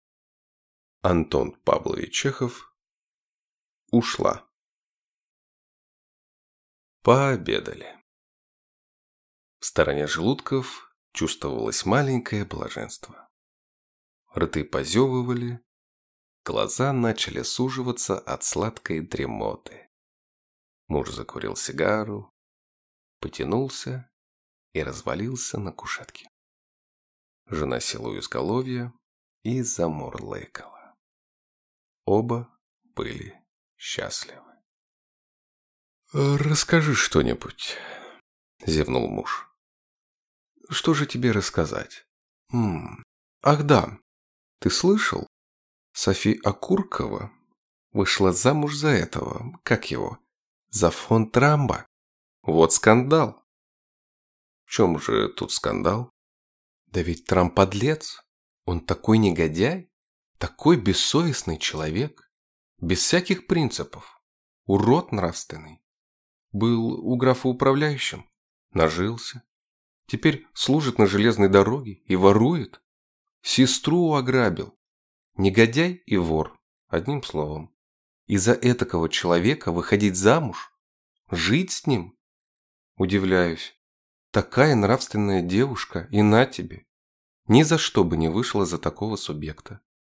Аудиокнига Ушла | Библиотека аудиокниг